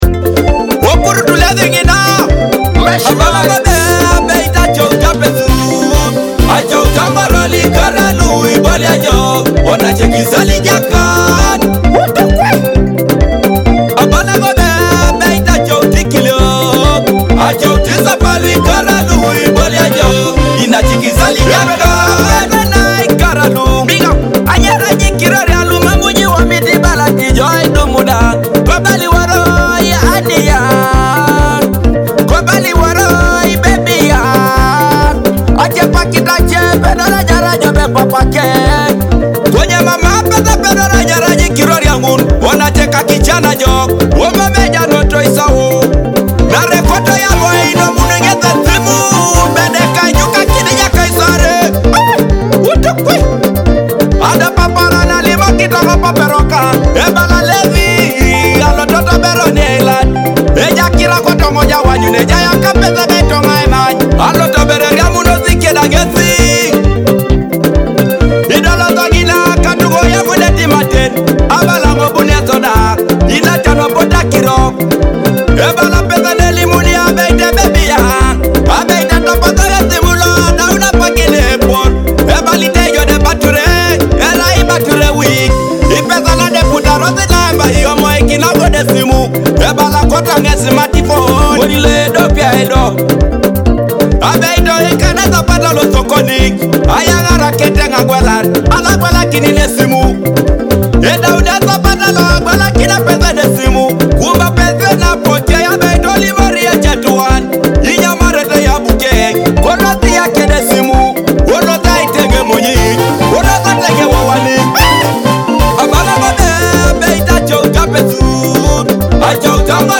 your top destination for authentic Teso music.